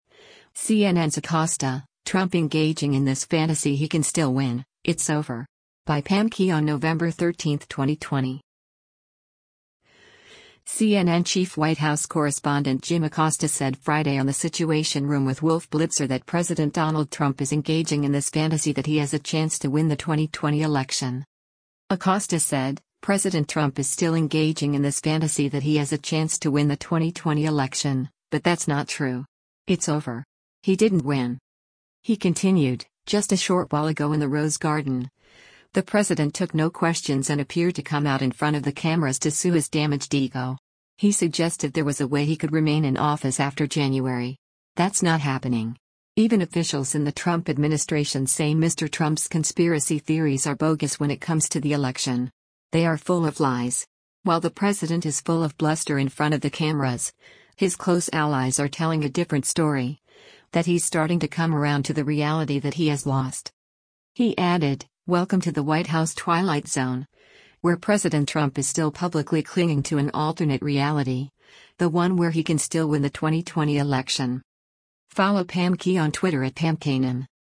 CNN chief White House correspondent Jim Acosta said Friday on “The Situation Room With Wolf Blitzer” that President Donald Trump is “engaging in this fantasy that he has a chance to win the 2020 election.”